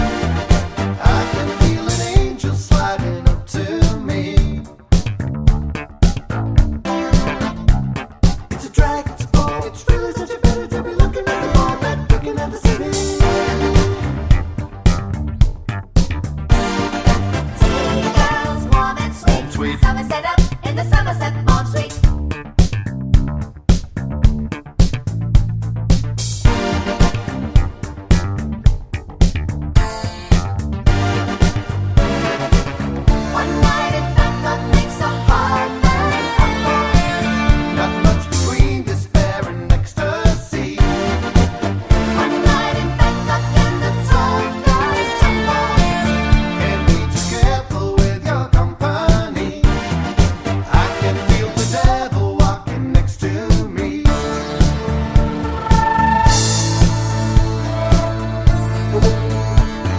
no Backing Vocals Musicals 3:20 Buy £1.50